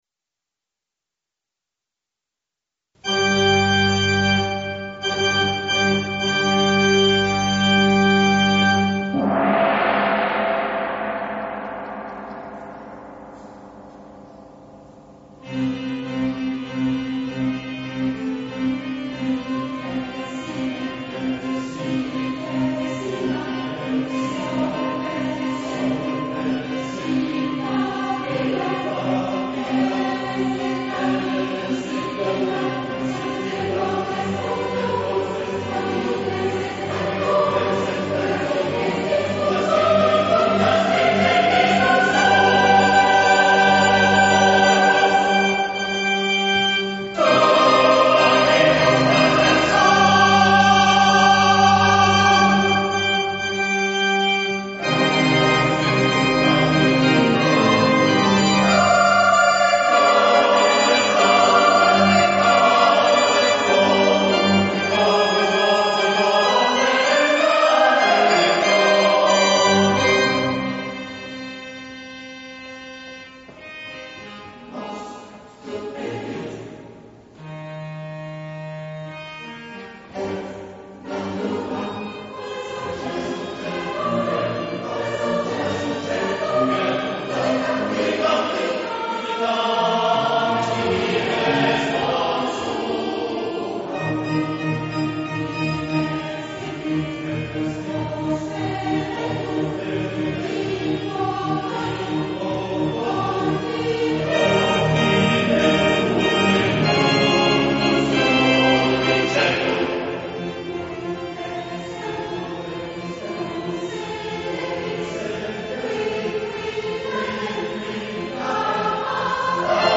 interprété par la chorale Saint-Joseph le 4 avril 2009 à Calais.